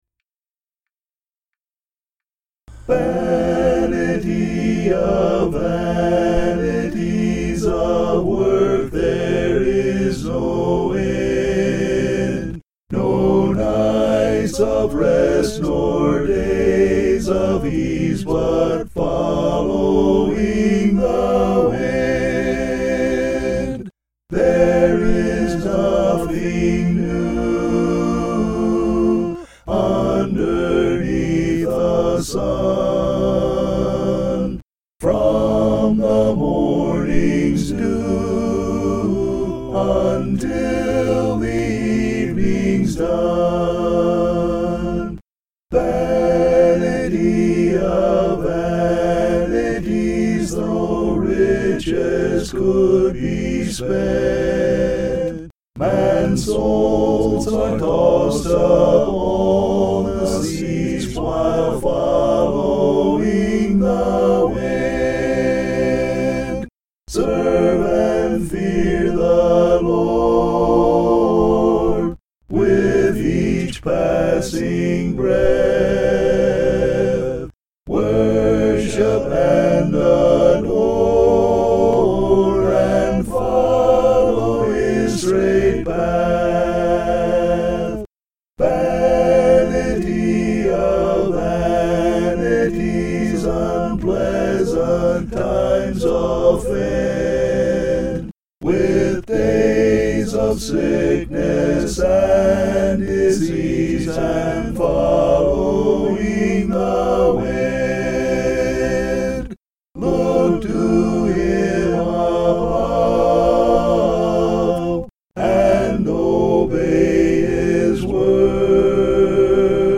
(An original hymn)
vocals